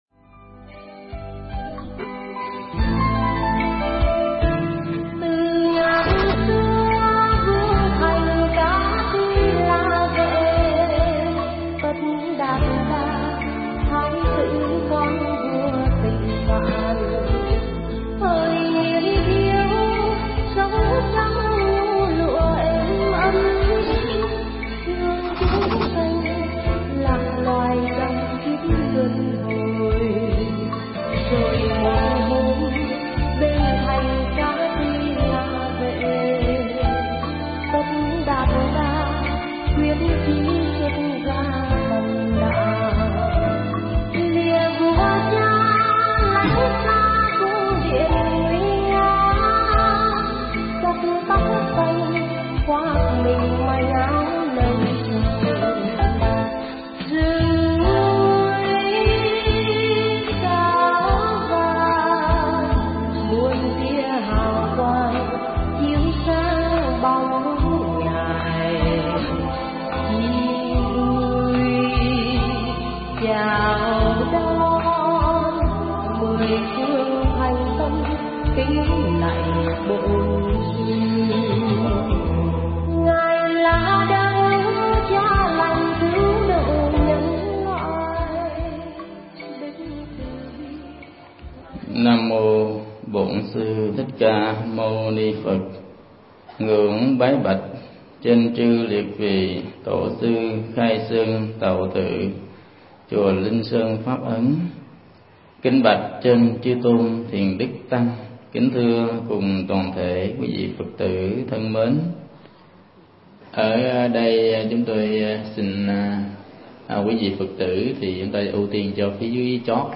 Mp3 Thuyết Giảng Tịnh độ vấn đáp 9
giảng tại chùa Linh Sơn Pháp Ấn